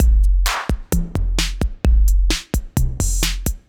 Index of /musicradar/80s-heat-samples/130bpm